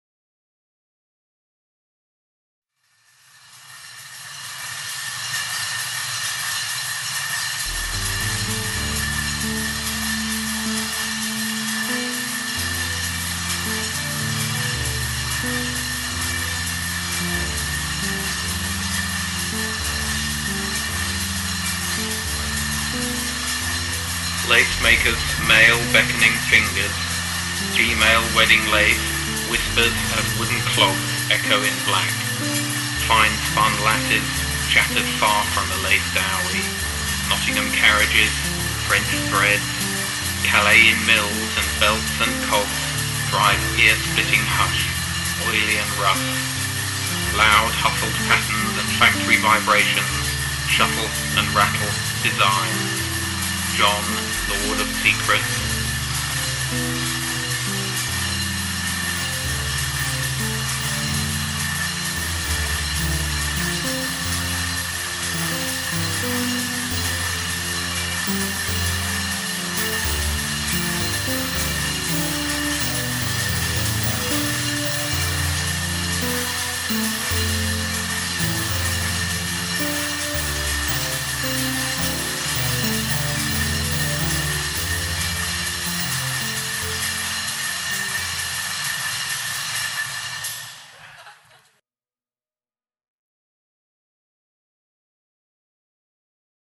A cut-up poem made about the lace machines was transformed into a soundtrack to accompany a 3D lightbox image of the ‘Lace Trance’ collage.
calais-soundscape.mp3